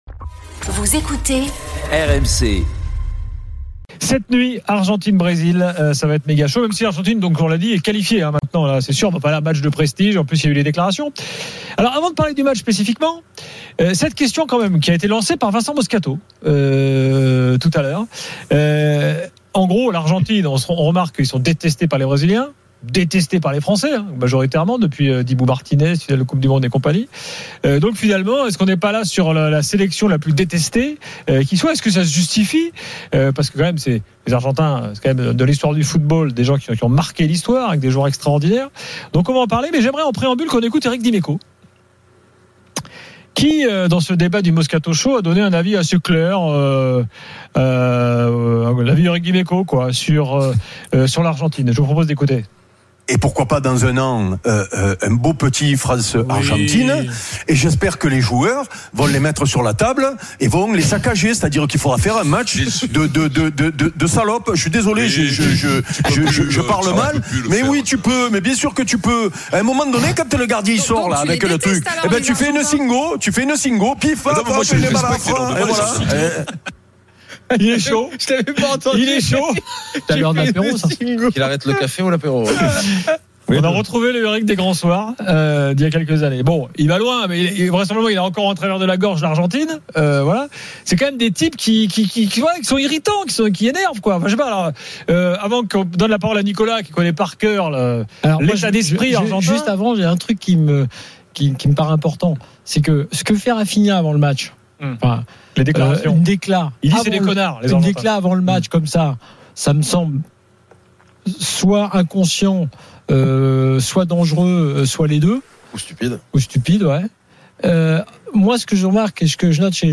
L’After foot, c’est LE show d’après-match et surtout la référence des fans de football depuis 19 ans !
Chaque jour, écoutez le Best-of de l'Afterfoot, sur RMC la radio du Sport !